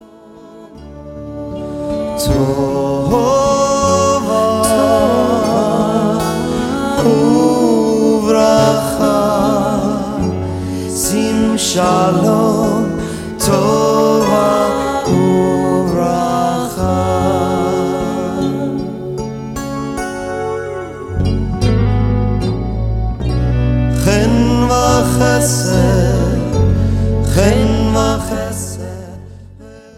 recorded with over 300 friends and fans!